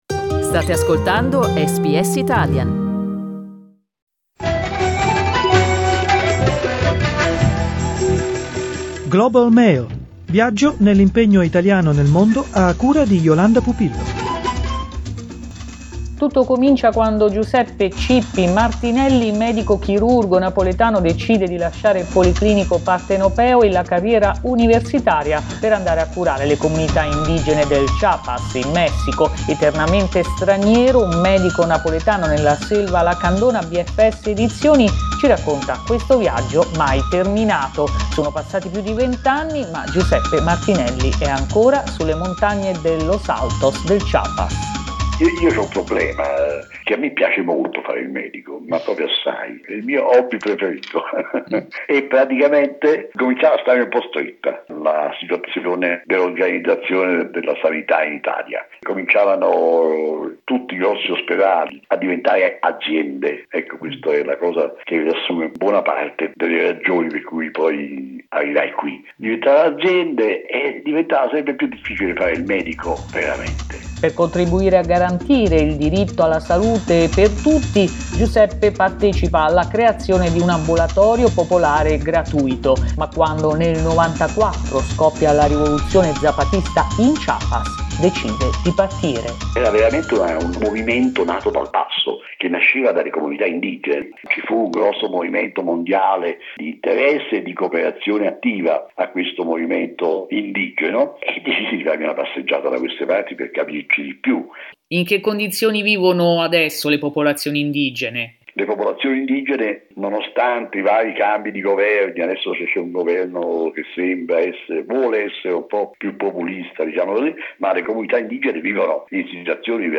In this interview with SBS Italian he also talks about the impact of the COVID-19 on the Maya descendants living in the Lacandon jungle in Chiapas.